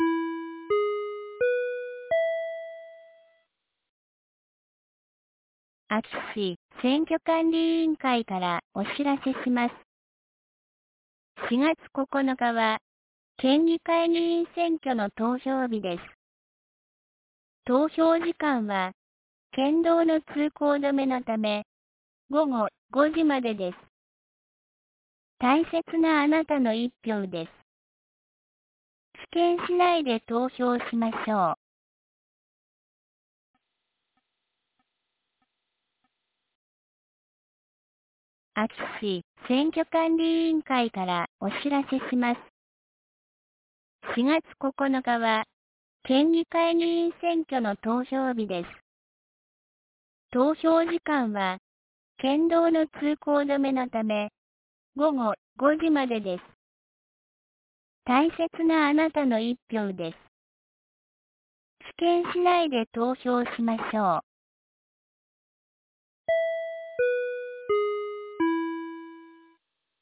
2023年04月03日 12時11分に、安芸市より畑山へ放送がありました。